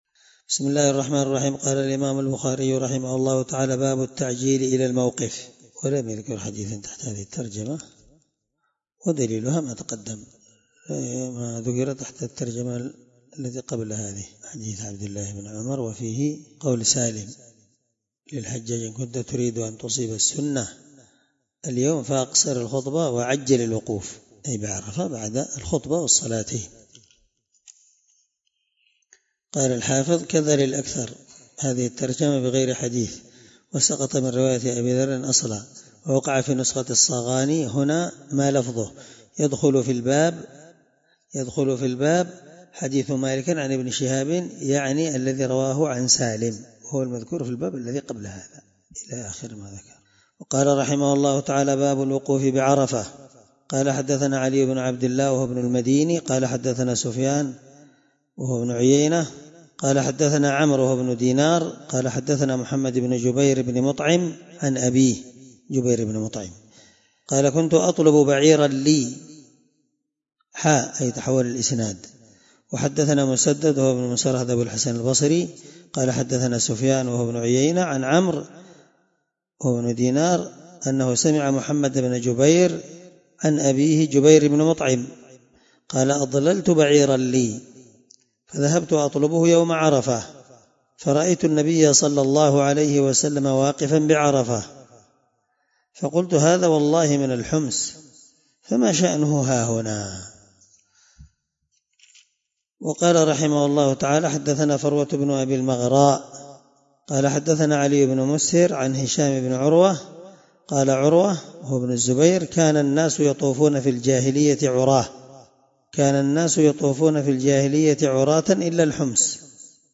الدرس62 من شرح كتاب الحج حديث رقم(1664-1665 )من صحيح البخاري